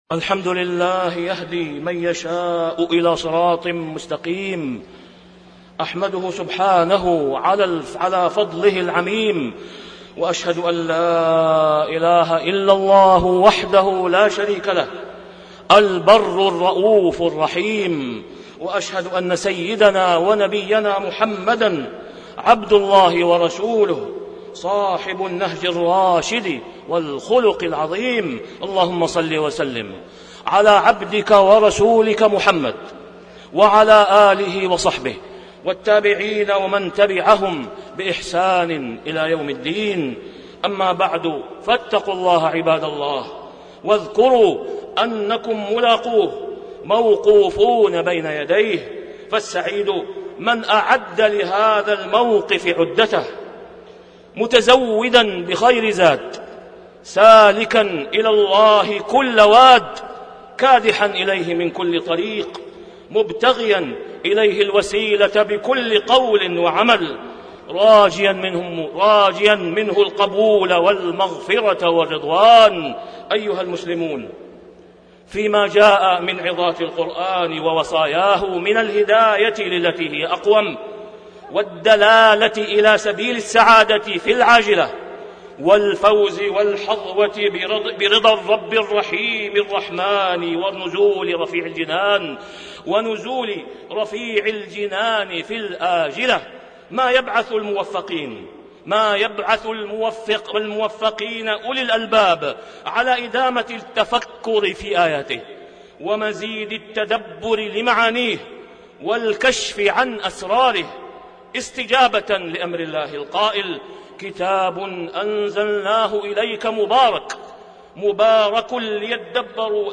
تاريخ النشر ١٢ ذو القعدة ١٤٣٣ هـ المكان: المسجد الحرام الشيخ: فضيلة الشيخ د. أسامة بن عبدالله خياط فضيلة الشيخ د. أسامة بن عبدالله خياط من وصايا القرآن الكريم